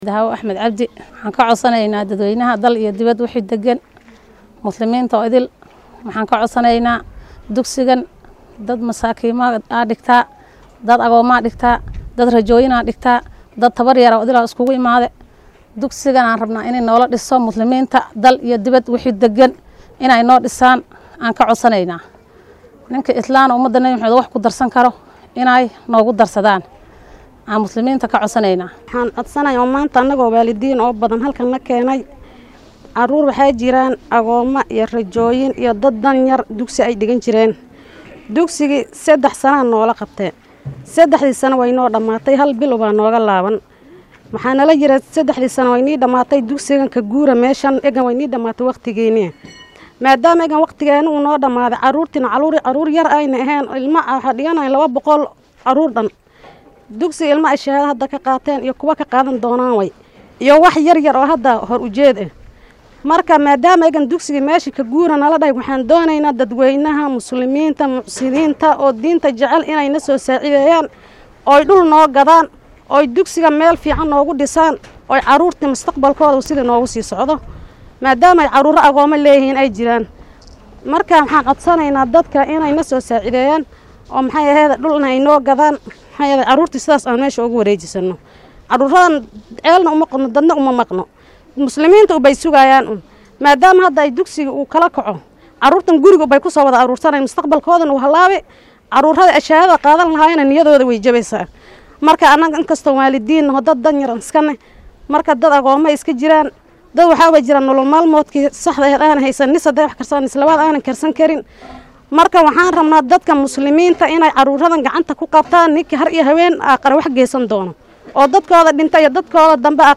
Macallimiinta dhiga dugsi lagu barto Quraanka Kariimka oo ku yaalla Garissa iyo waaliidiinta dhalay ardayda xaruntaasi wax ka barta ayaa bulshada ka codsaday in ay ka qayb qaadato sidii dugsigaasi loogu iibin lahaa dhul u gaar ah. Macallimiinta iyo qaar ka mid ah waalidiinta oo warbaahinta Star la hadlay ayaa sidatan yiri.